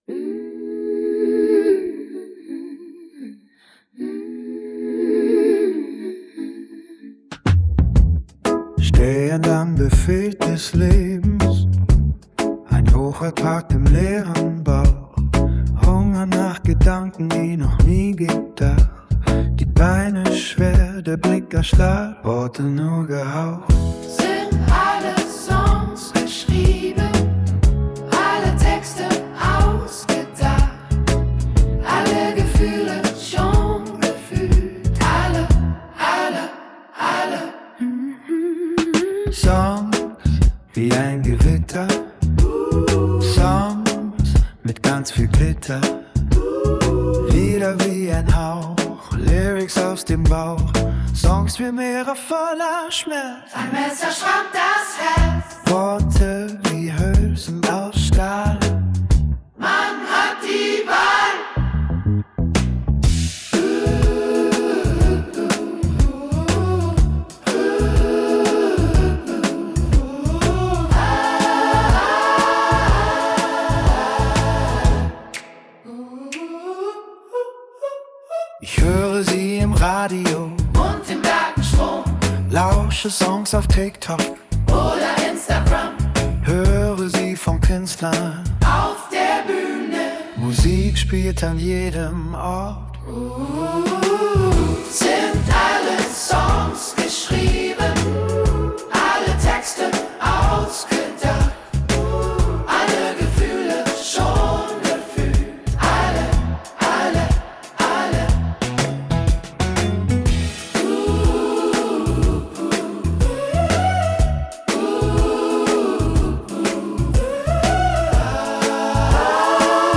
“Alle Songs” – Deutsch, männlicher Singer-Songwriter, Chor, Downtempo